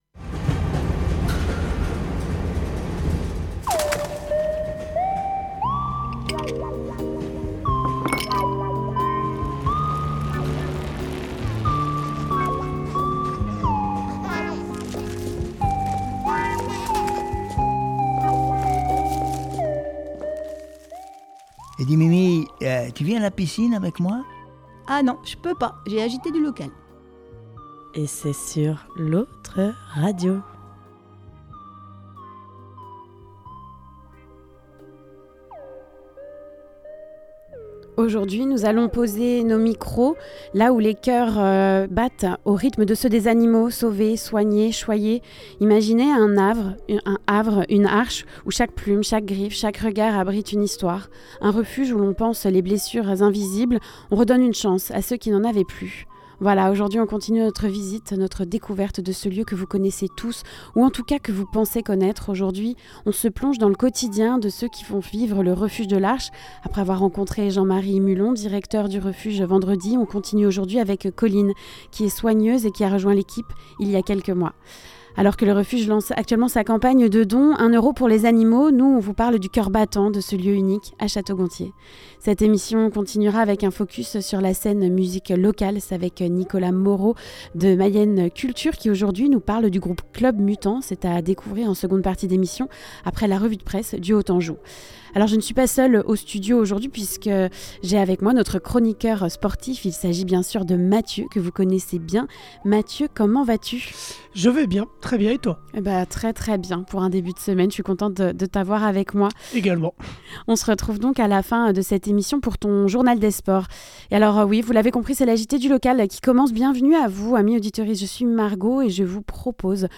La revue de presse du Haut Anjou